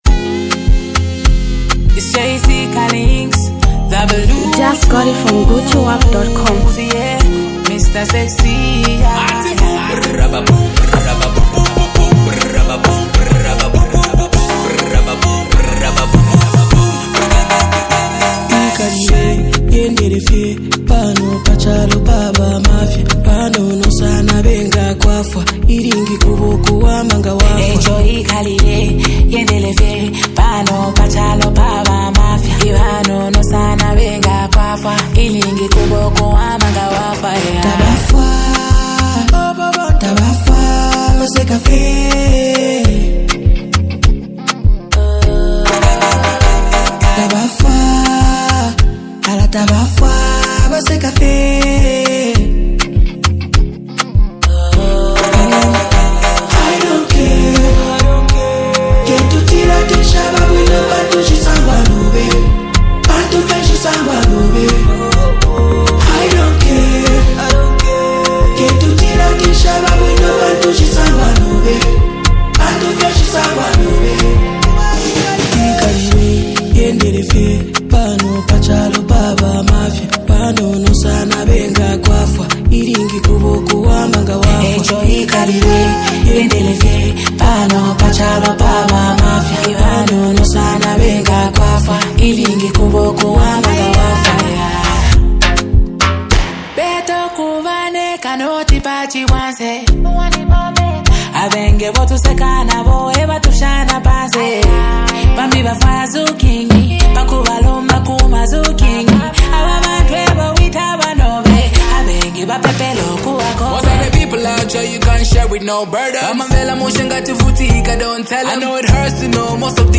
Rhythmic synergy ignites
a vibrant collaboration